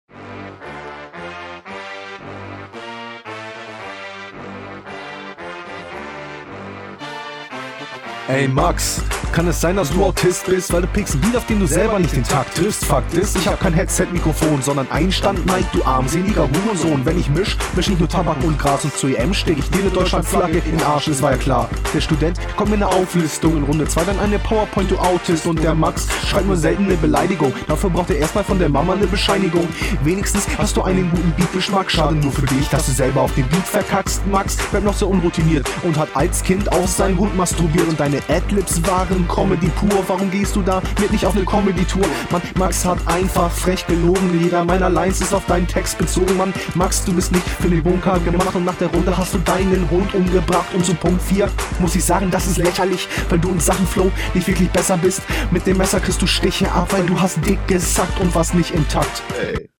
Du kommst wirklich viel besser auf den Beat, konterst gut die Zeilen deines Gegners.